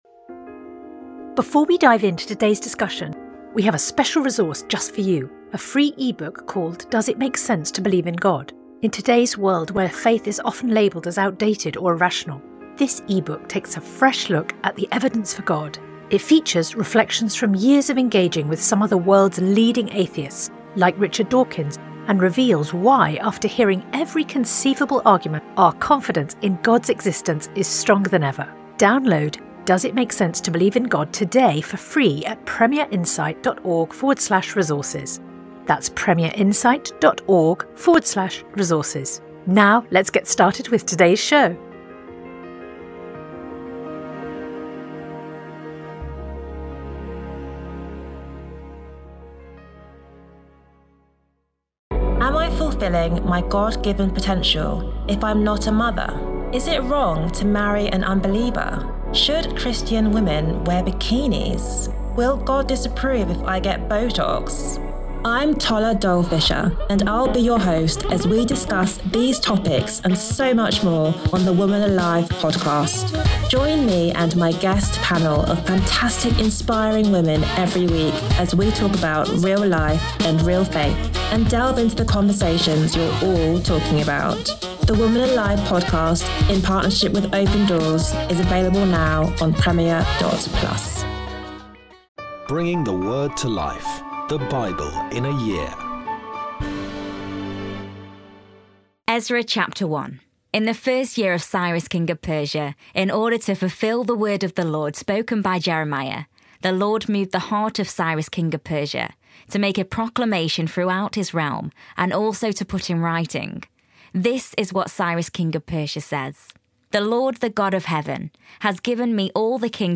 Today’s readings comes from Ezra 1-2; Psalms 84 Sponsored ad Sponsored ad